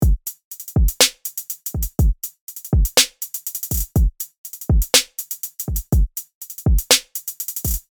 今回は、1拍目（小節の始まり）にキック、3拍目にスネアを配置し、ハーフビートのパターンを作ります。
ハイハットは多くの音楽ジャンルで使用され、規則的なリズムで打たれることが多いですが、今回はトラップやフューチャーベースなどのエレクトロダンスミュージックからインスパイアされた不規則なパターンを作成します。
今回は、2拍目や4拍目に対して3分割を行い、より強い変化を加えます。
今回は、HIPHOP系でよく聞かれるサウンドが特徴の「808 Flex」を選択します。
▶音色を変えた後のドラム
808flex.mp3